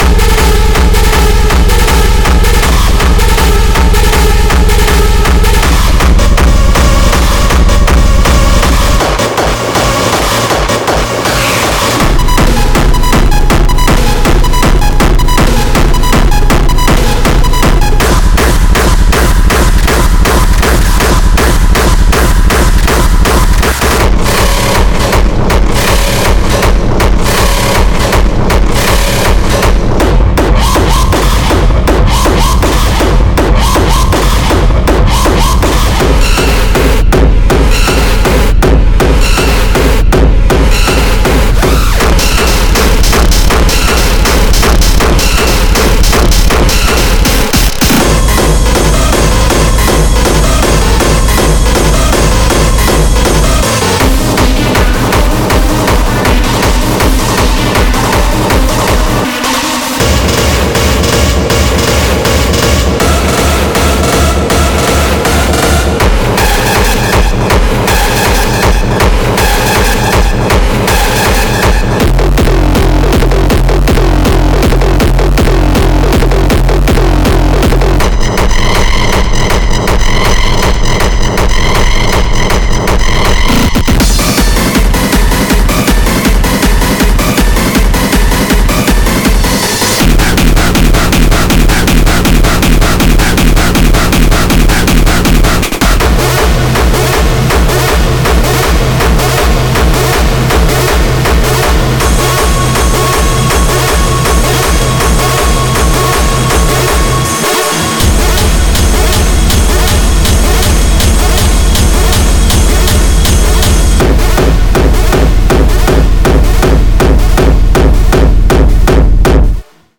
ハードなエレクトロニックミュージック制作に使える、100%ライセンスフリーの強烈なサウンドコレクションです。
モンスタ―キック、パーカッション、ヘヴィなリード、鋭いスクリーチなどが収録されています。
デモサウンドはコチラ↓
Genre:Hard Dance